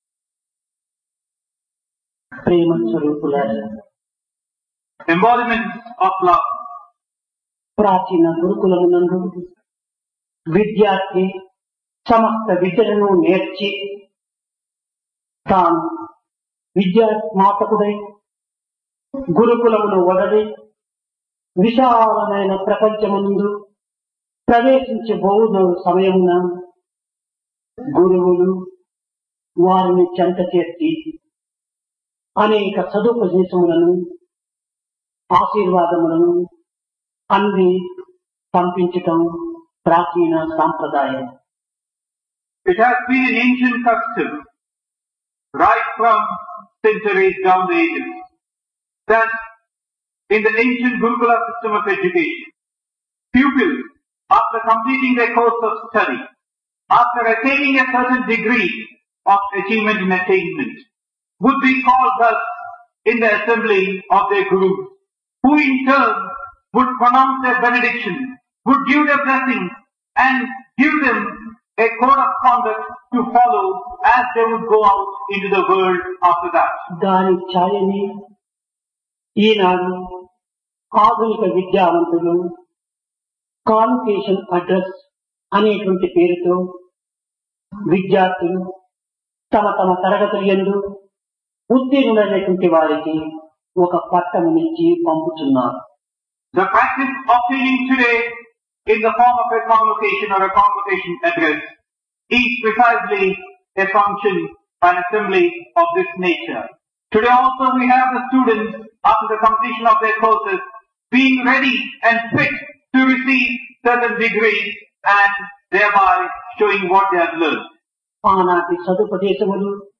Convocation - Divine Discourse | Sri Sathya Sai Speaks
Place Prasanthi Nilayam Occasion Convocation - 1